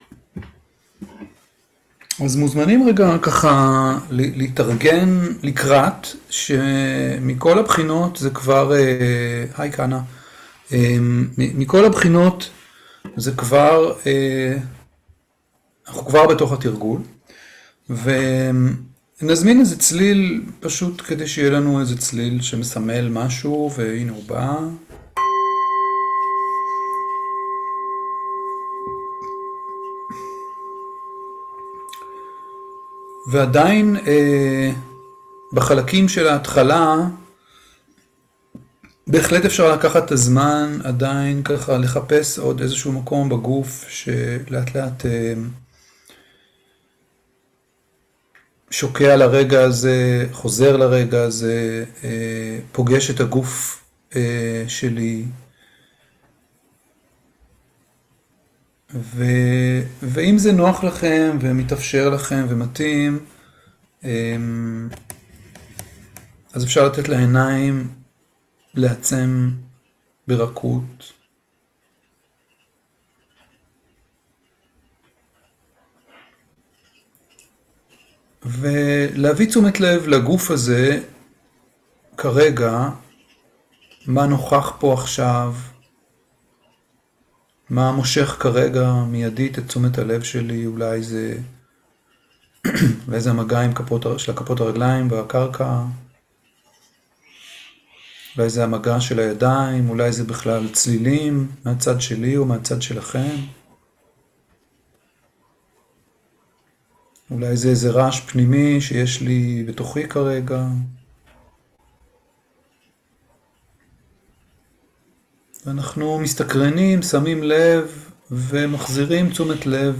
17.10.2023 - מרחב בטוח - מדיטציה מונחית (חלק א)